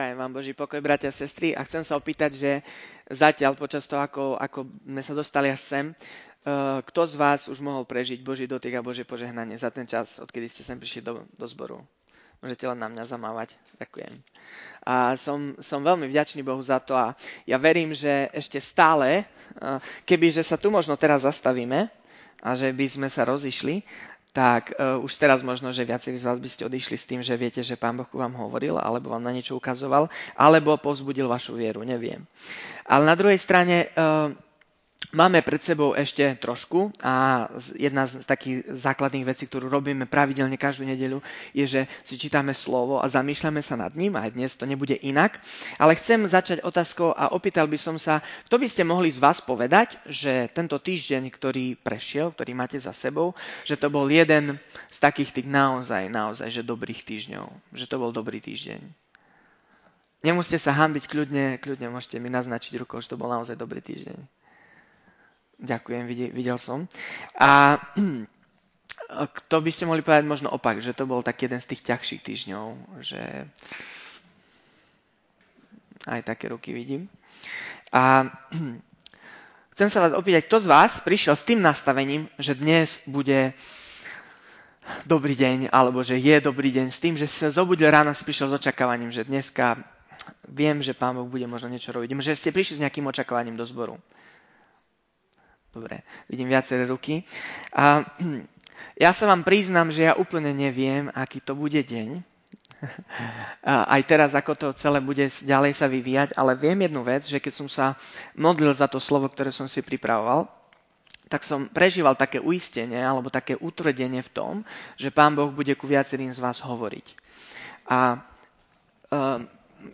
Vypočujte si kázne z našich Bohoslužieb